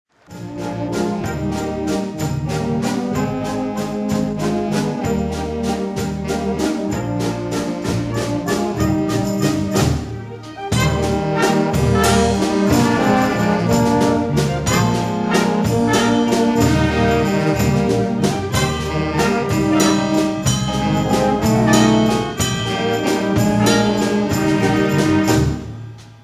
Konzert 2004 -Download-Bereich